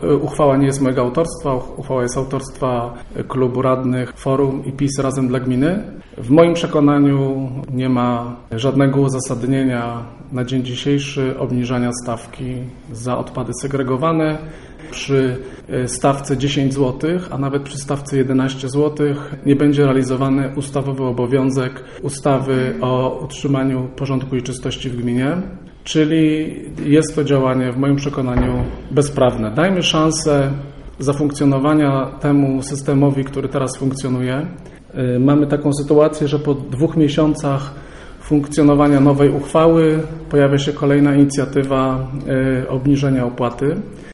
Dziś w auli Urzędu Miejskiego w Żninie odbyła się sesja rady miasta.
Podczas obrad żnińscy rajcy zastanawiali się nad pojęciem uchwały zmieniającej uchwałę w sprawie ustalenia stawek opłat za gospodarowanie odpadami komunalnymi, która przypomnijmy przyjęta została 28 października, a obowiązuje od stycznia tego roku, mówi burmistrz Robert Luchowski.